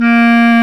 WND BSCLAR07.wav